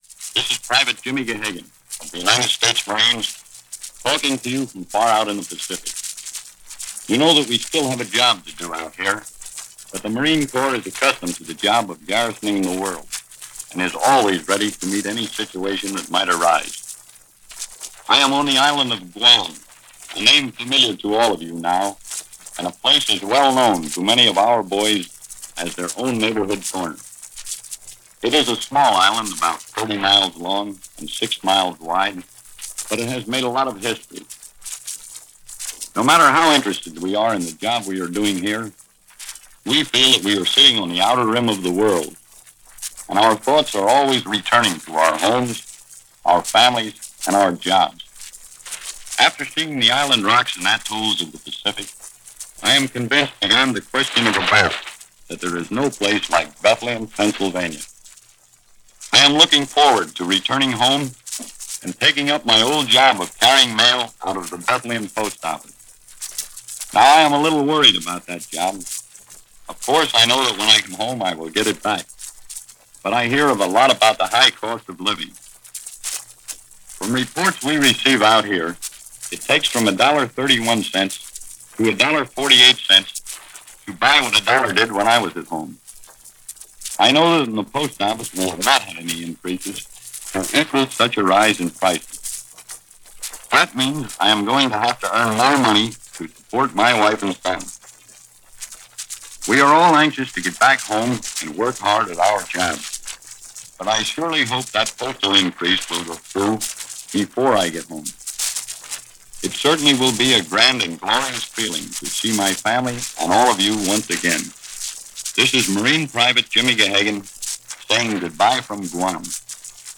And that’s some of what was going on, this first day of March in 1945 as present by reports from Mutual News.